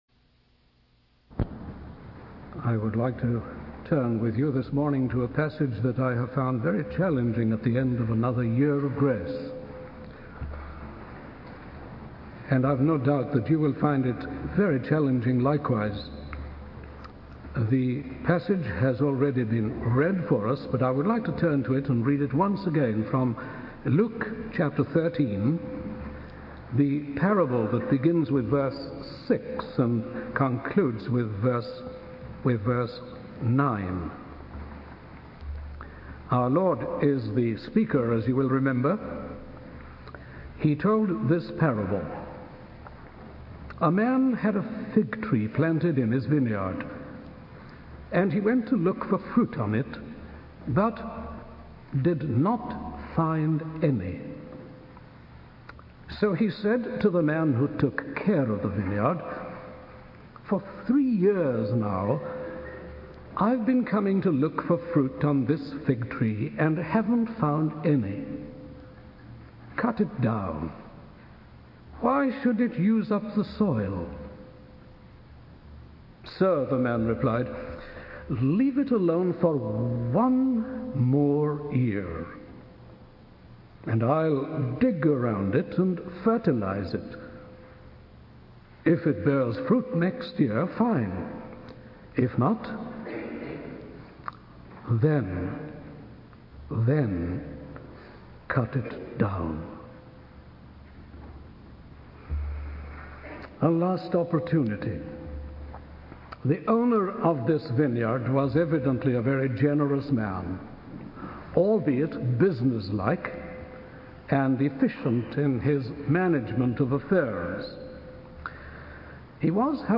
In this sermon, the speaker reflects on a parable about a barren fig tree and its implications for our lives. The parable emphasizes the importance of producing fruit and the consequences of not doing so.